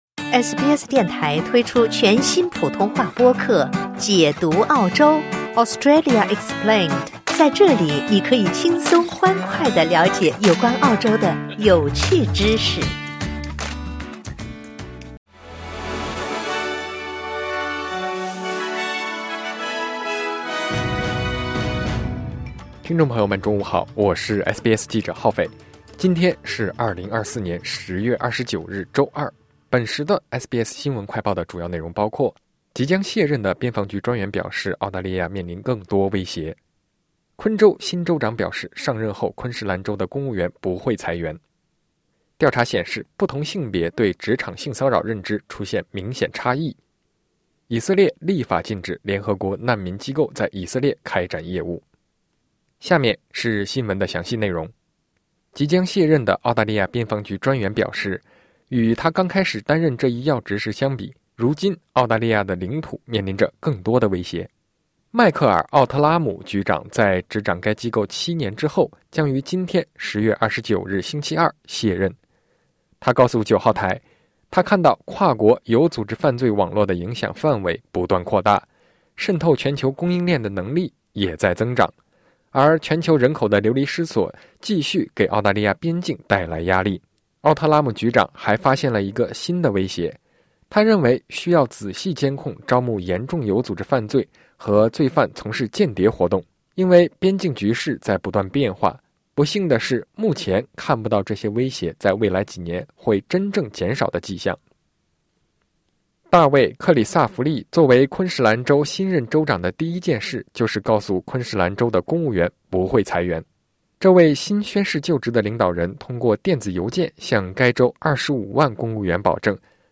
新闻快报